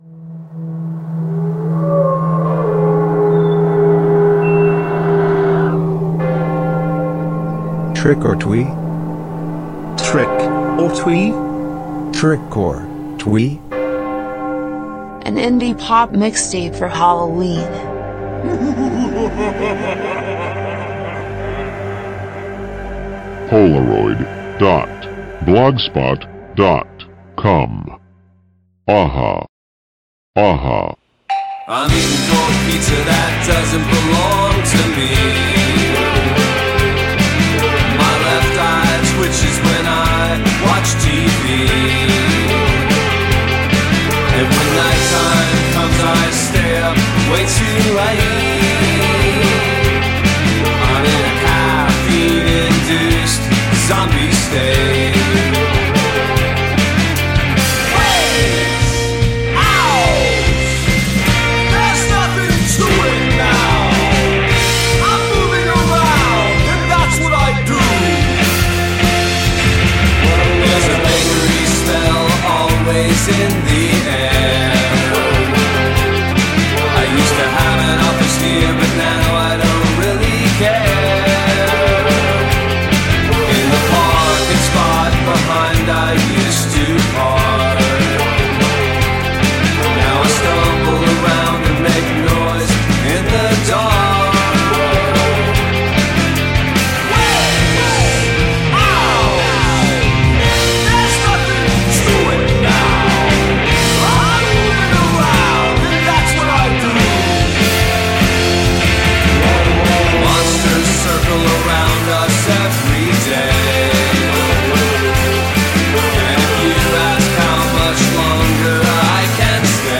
Indiepop, indie rock e brindisi!